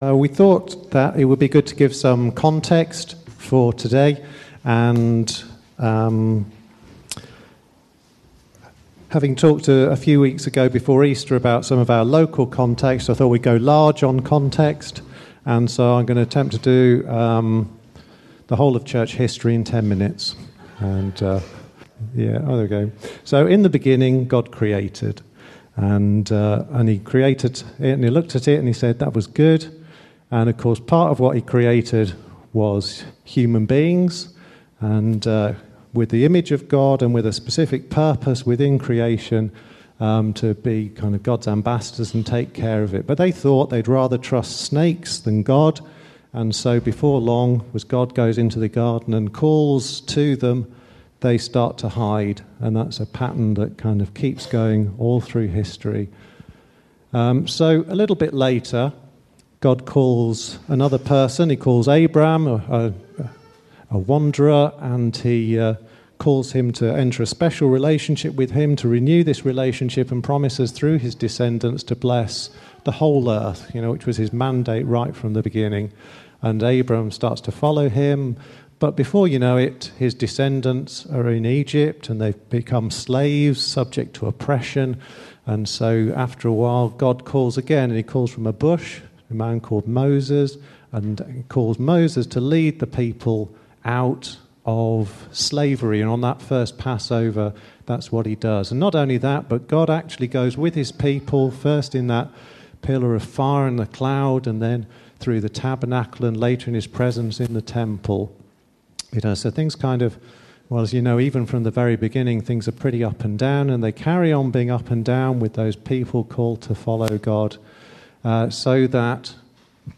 From the church vision day – our (big) context.